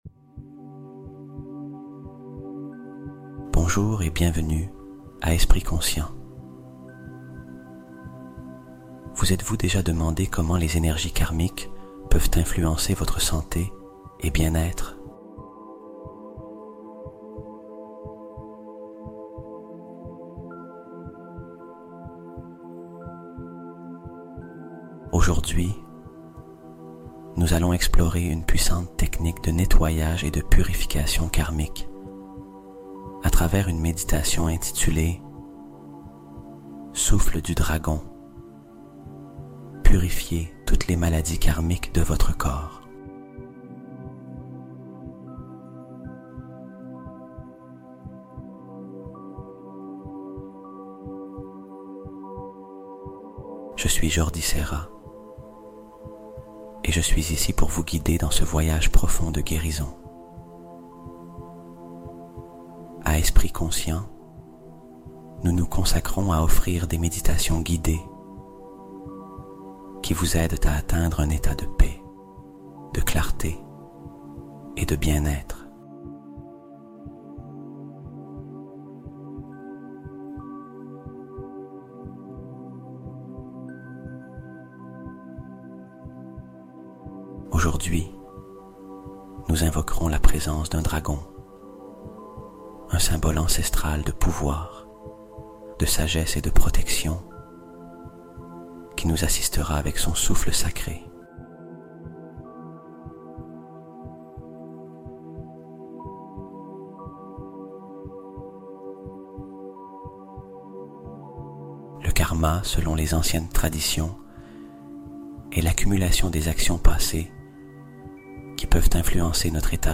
EFFATÁ : Le Mot Qui Débloque Miracles et Richesses Instantanément | 432Hz Fréquence Divine